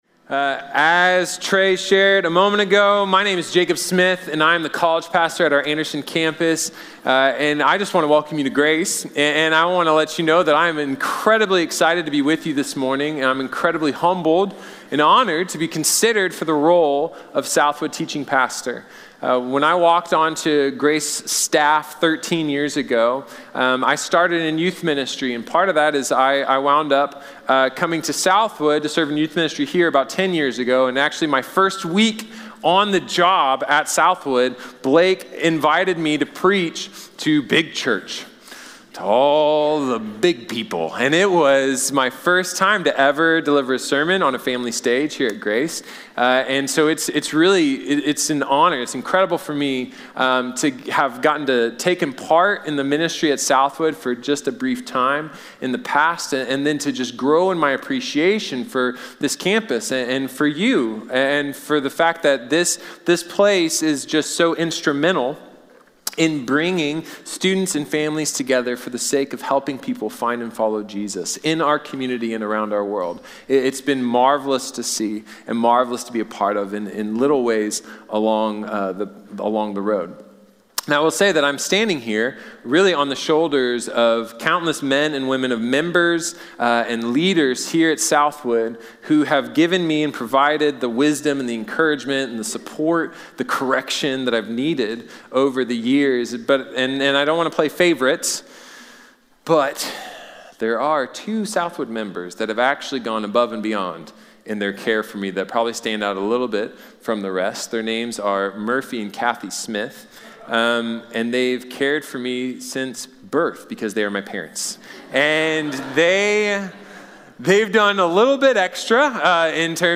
Perspective in Pain | Sermon | Grace Bible Church